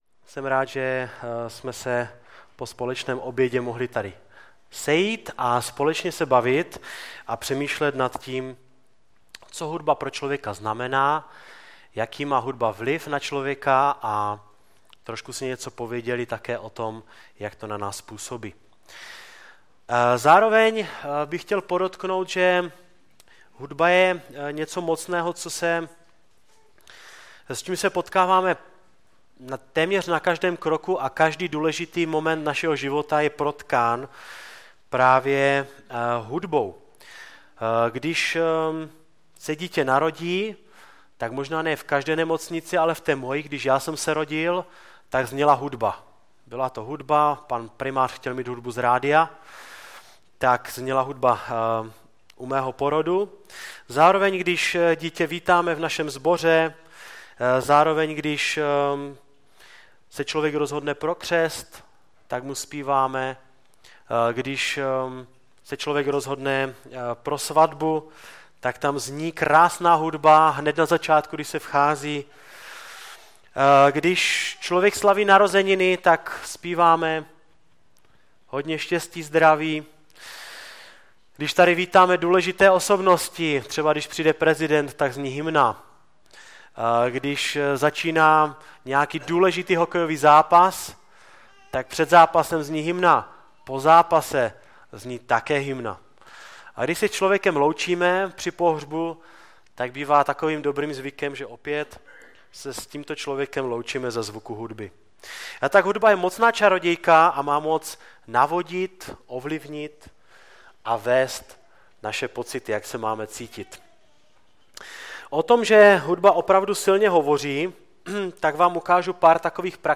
Typ Služby: Přednáška